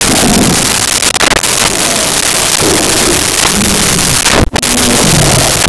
Bengal Tiger Roars 02
Bengal Tiger Roars 02.wav